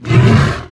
role7_die2.wav